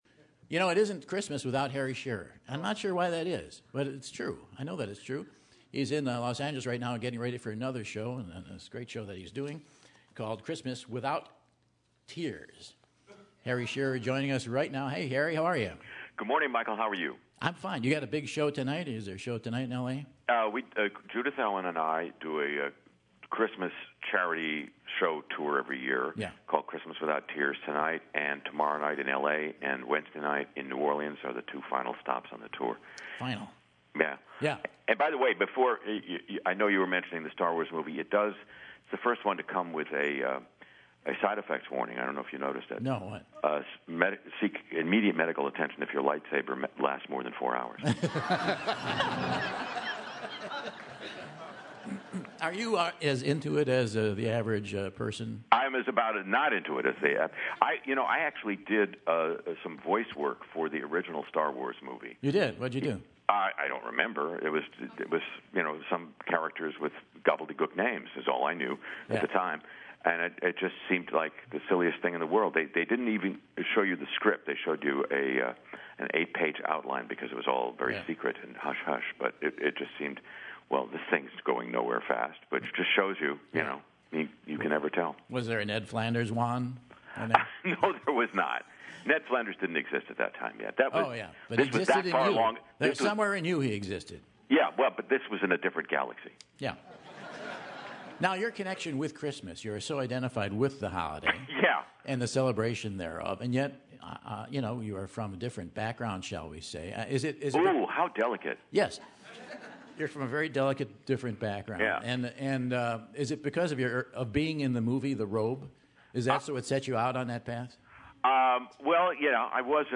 It's the holiday season and Michael calls up legendary actor/voice talent/radio host Harry Shearer to chat about his Christmas Without Tears tour and EP that he produces with his wife Judith Owen!